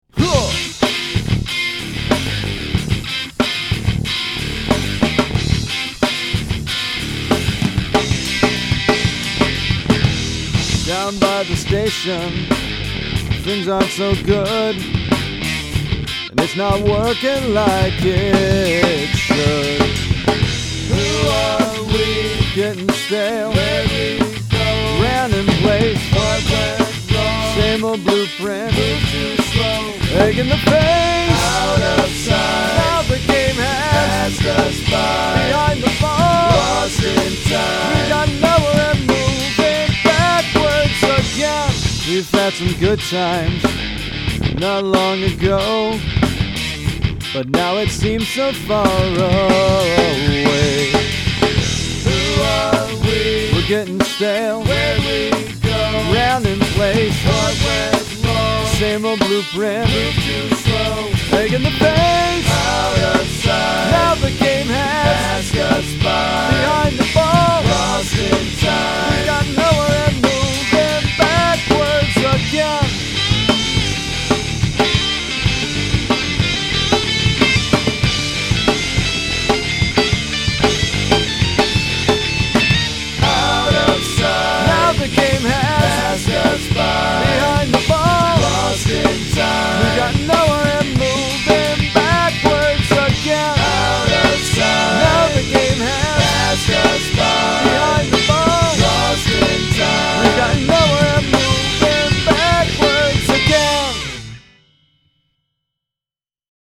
Both bands hit pretty hard and groove like crazy, but I leaned far more towards King’s X in the song’s details (Drop-D tuning, three-part vocal harmonies).